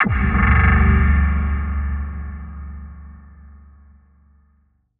Processed Hits 11.wav